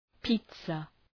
{‘pi:tsə}